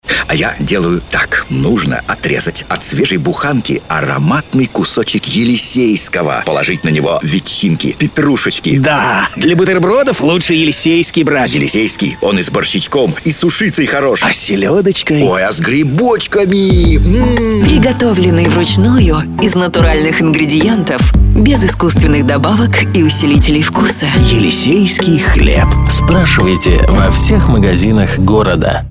Началось размещение рекламы на радиостанции "Дорожное Радио" компании "Елисейский хлеб" в Твери.